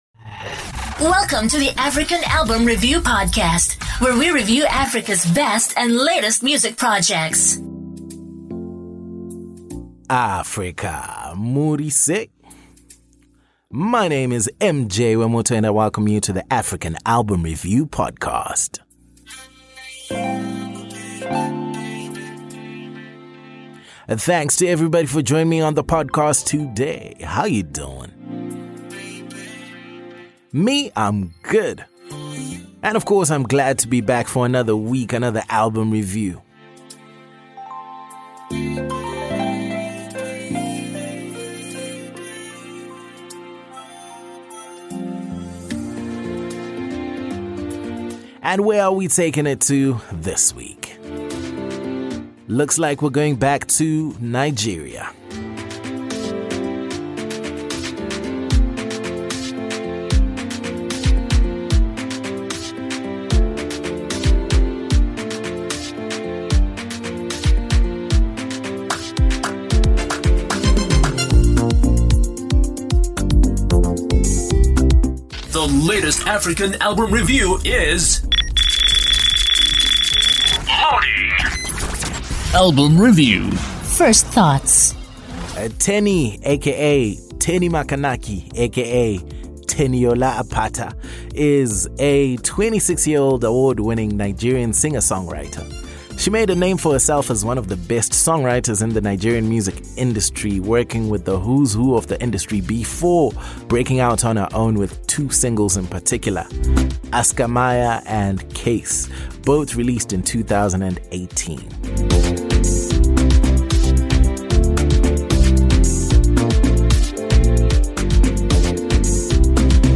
Teni: Billionaire | EP REVIEW Nigeria ~ African Album Review Podcast